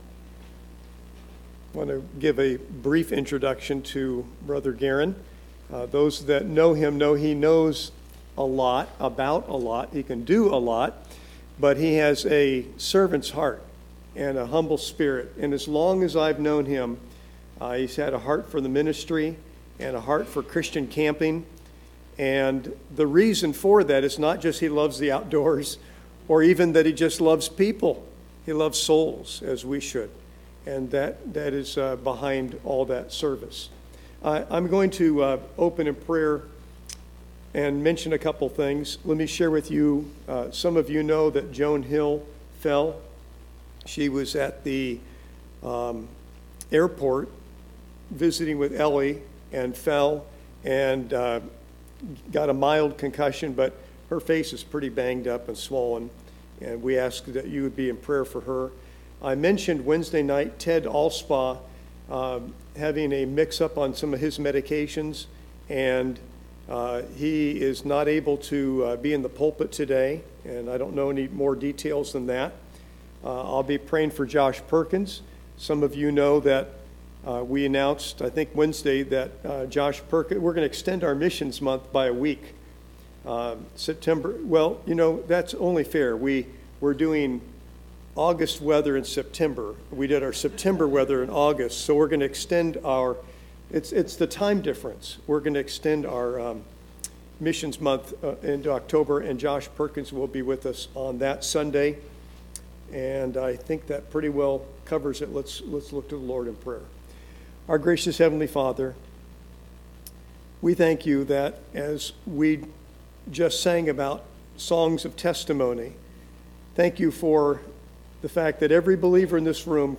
ServiceMissionary ConferenceSunday Morning